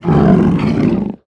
swipekill.wav